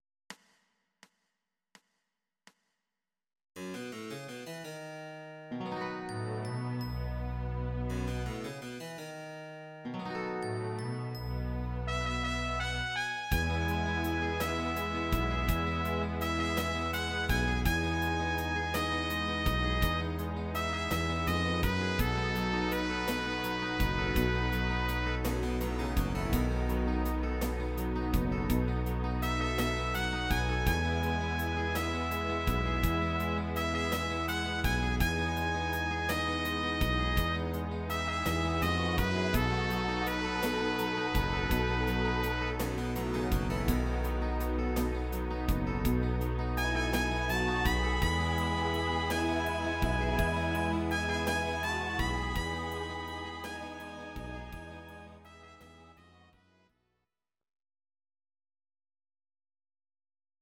Audio Recordings based on Midi-files
Jazz/Big Band, Instrumental, 1970s